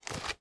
High Quality Footsteps
STEPS Dirt, Walk 26.wav